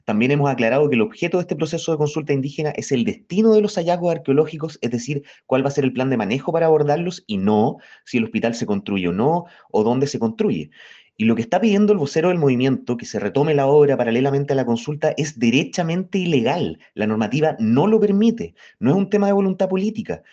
Mientras que, el seremi de Gobierno, Juan Guerra, fue enfático en responder al dirigente que la solicitud para reactivar los trabajos para el Hospital de La Unión no responde a un tema de voluntad política, sino que derechamente es ilegal.